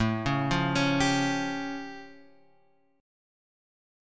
A Augmented 7th